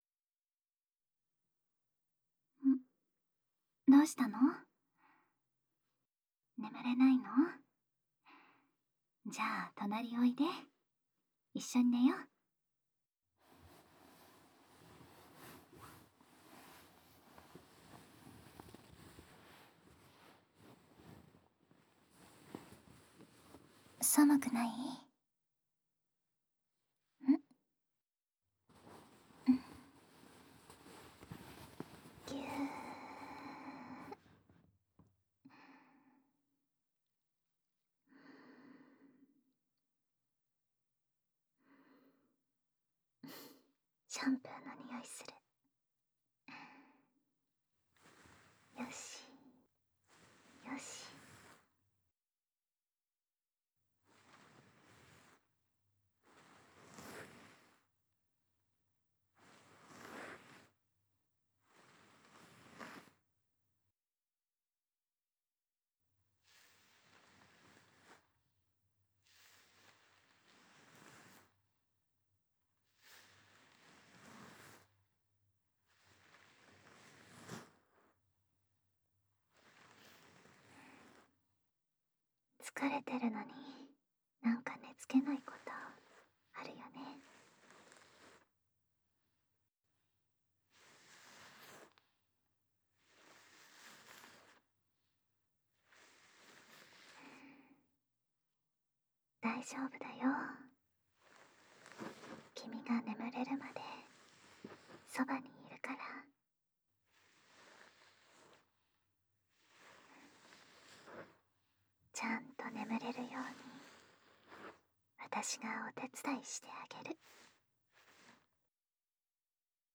环绕音 ASMR
05 添い寝.m4a